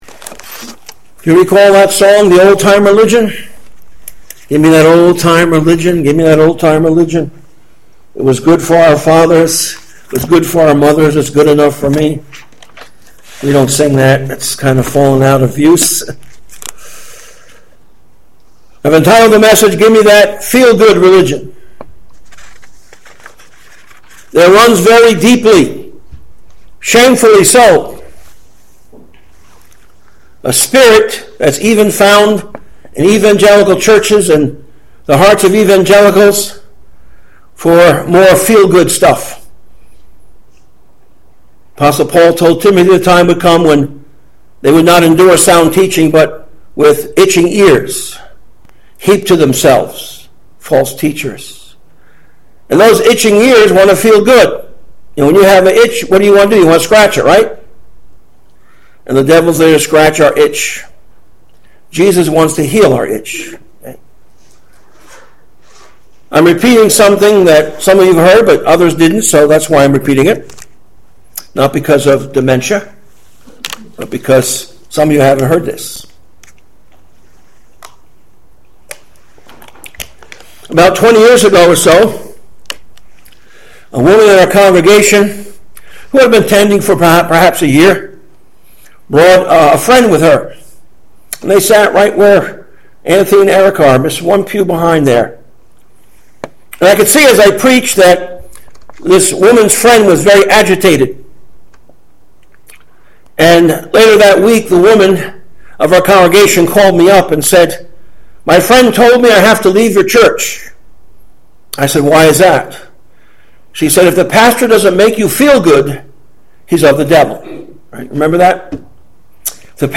Sermons/Bible Studies from previous years;